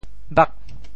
潮州 bhag8 hê5 潮阳 bhag8 hê5 潮州 0 1 潮阳 0 1